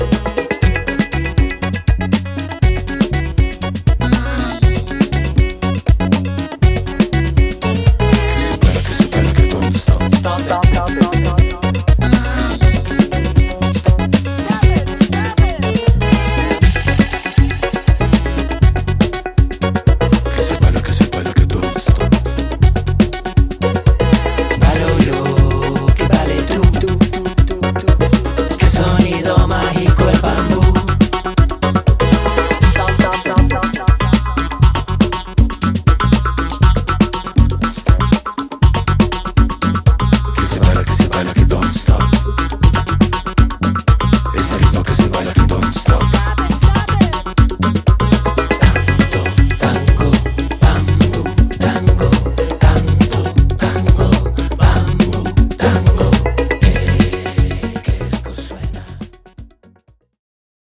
同テイストで跳ねたタンゴのリズムも楽しげなA面もナイス。